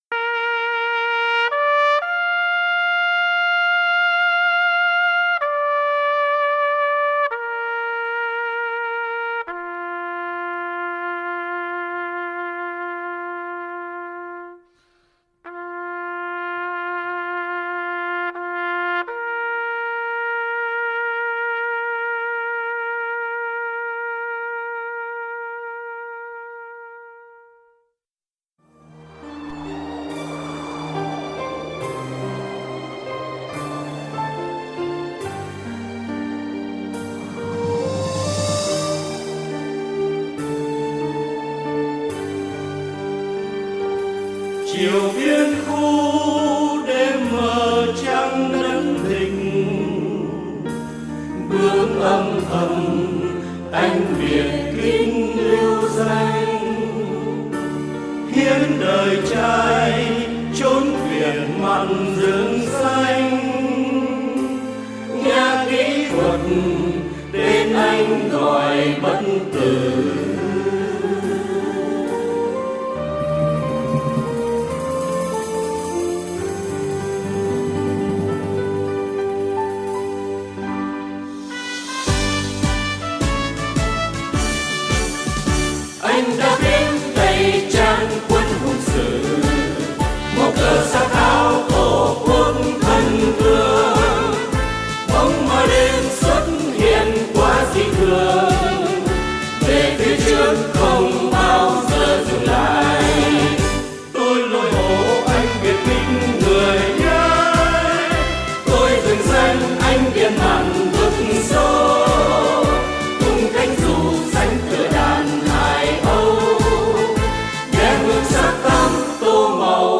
Chủ đề: nhạc lính